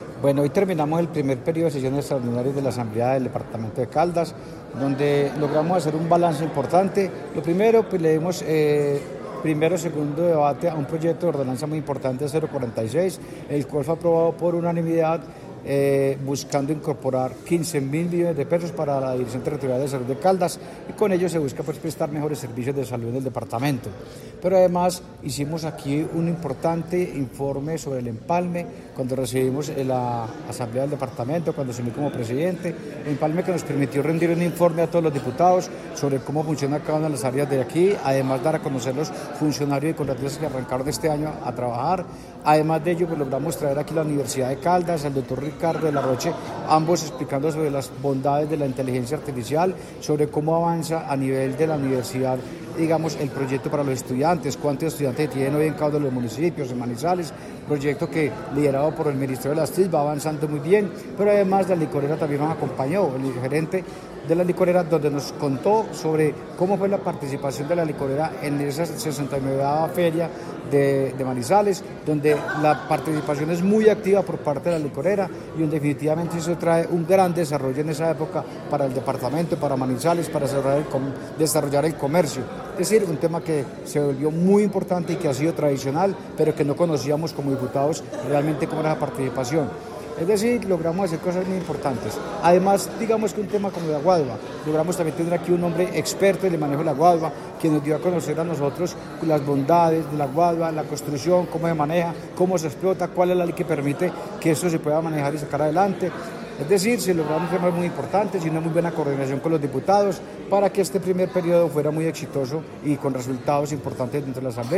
Presidente de la Asamblea, Jahír de Jesús Álvarez.
Jahir-de-Jesus-Alvarez-presidente-Asamblea-de-Caldas.mp3